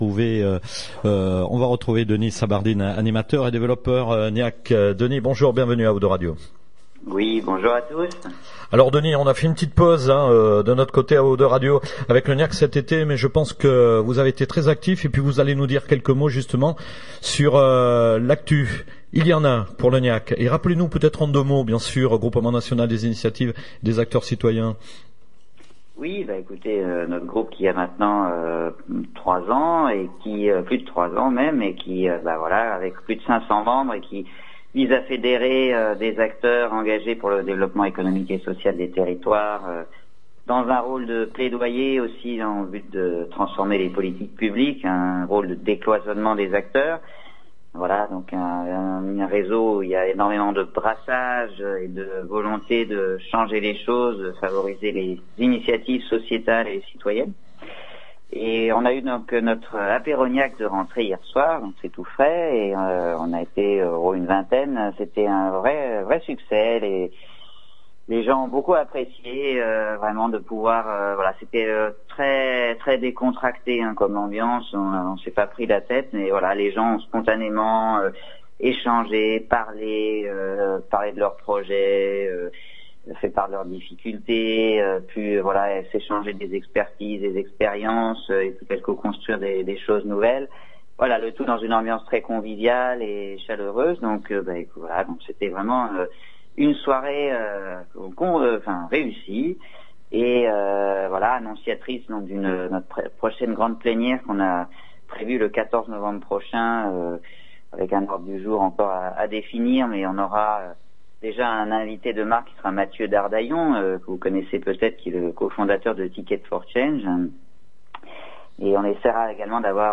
Interview GNIAC/ O2 Radio